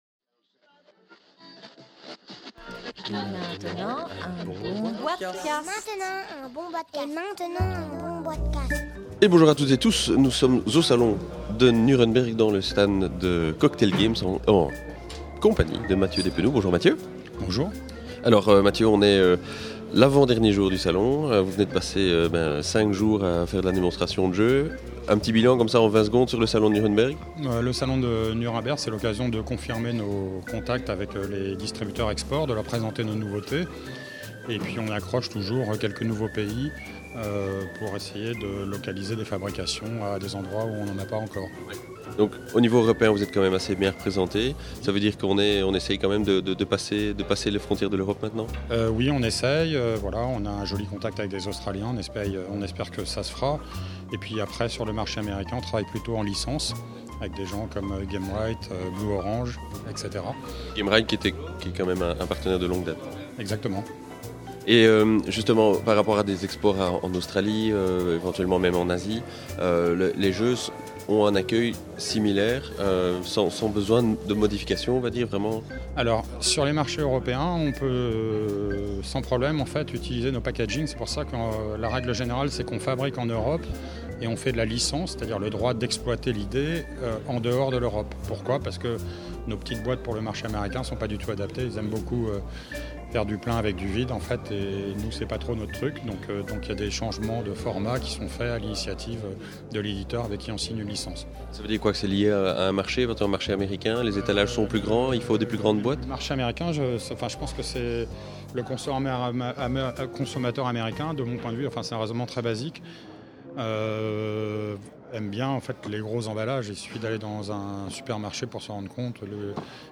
(enregistré en février 2012 lors de la Nuremberg Toy Fair)